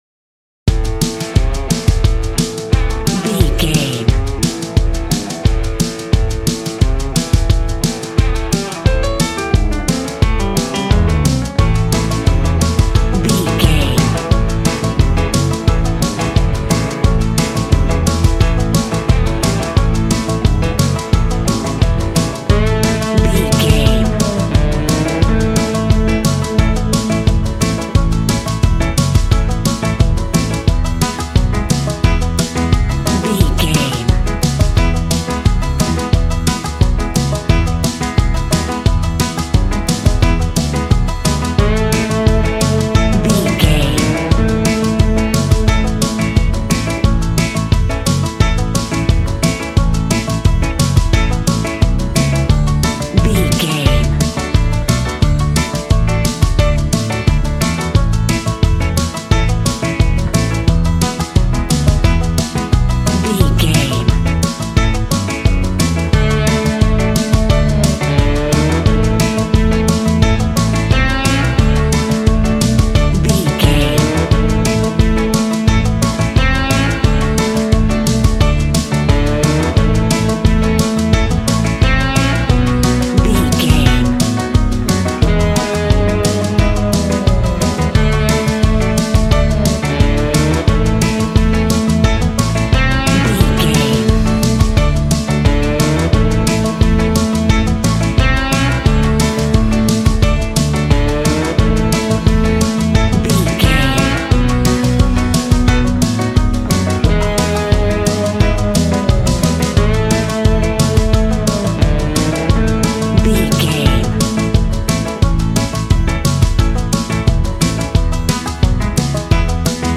Country music for a cowboy rodeo show.
Ionian/Major
Fast
fun
bouncy
double bass
drums
acoustic guitar